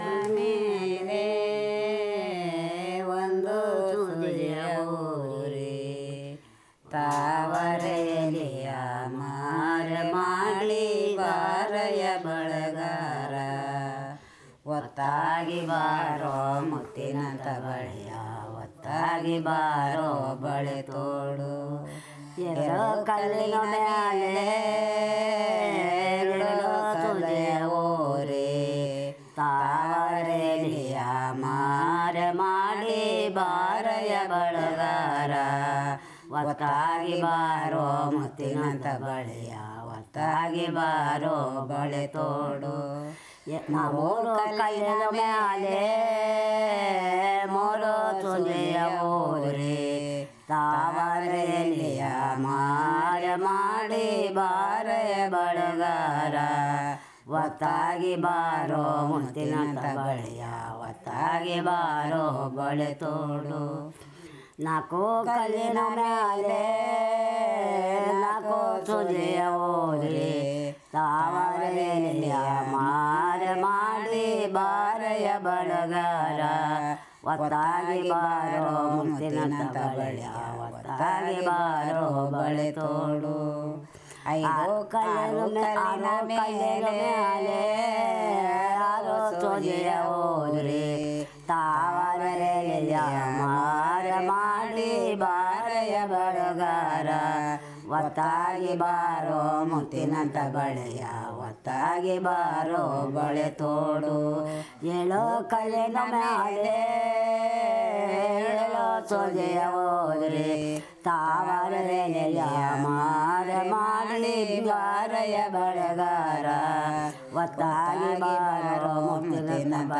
Performance of Jenu Kuruba tribe folk song about wearing new bangles